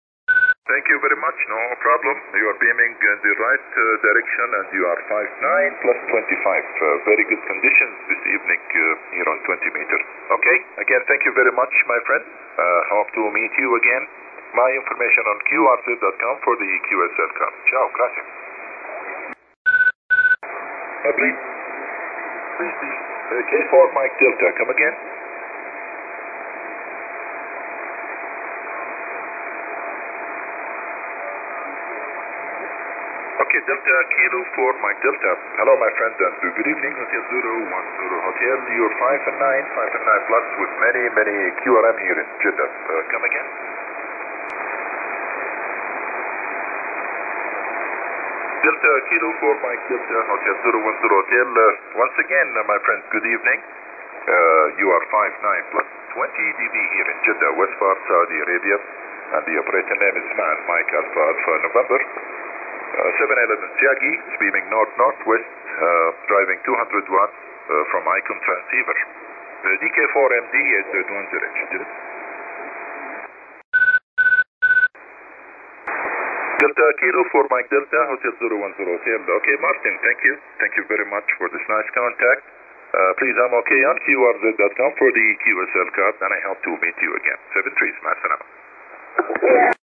I’ve deliberately selected signals right on the limit, to show the capabilities of the aerials, after all, there’s little point in comparing strength 9 signals on the doorstep.
I have marked this with one ‘beep’ in the recordings.
I’ve marked the SRC X80 with two ‘beeps’ in the recordings.
I’ve marked this with three ‘beeps’ in the recordings.
First the trapped dipole, then the X80, then the Steppir.